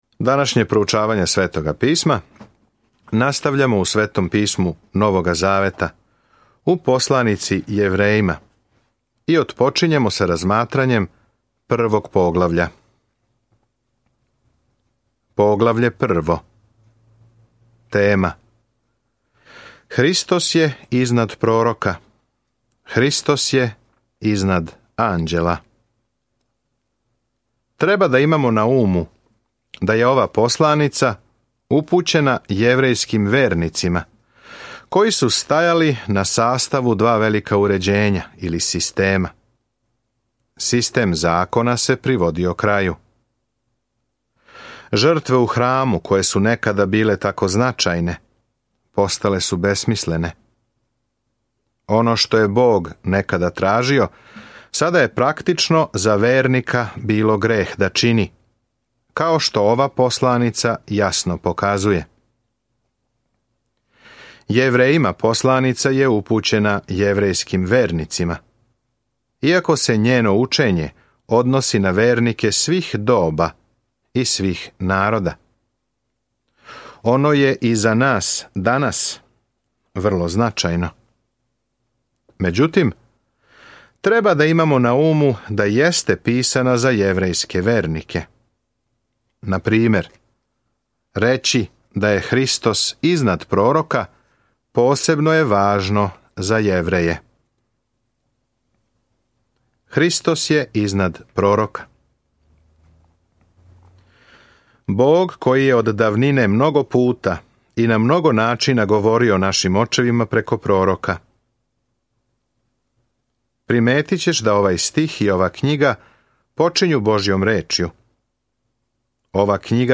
Свакодневно путујте кроз Јевреје док слушате аудио студију и читате одабране стихове из Божје речи.